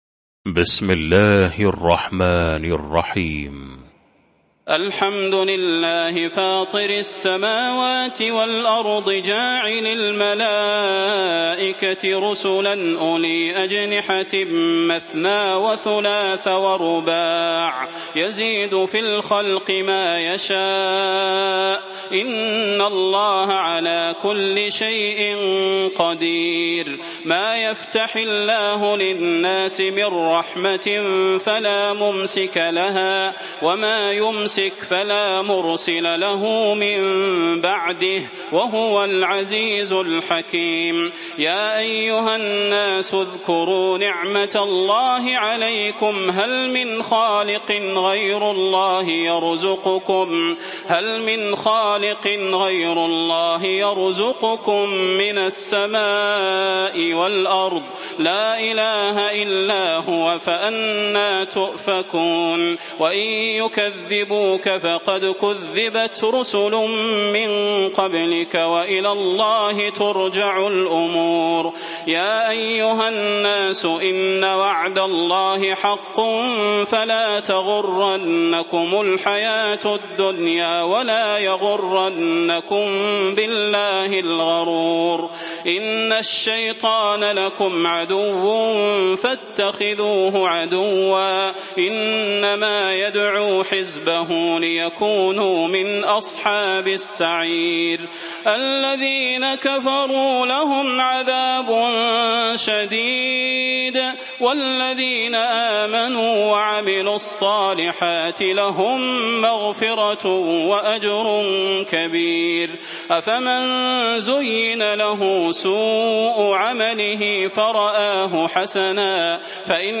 المكان: المسجد النبوي الشيخ: فضيلة الشيخ د. صلاح بن محمد البدير فضيلة الشيخ د. صلاح بن محمد البدير فاطر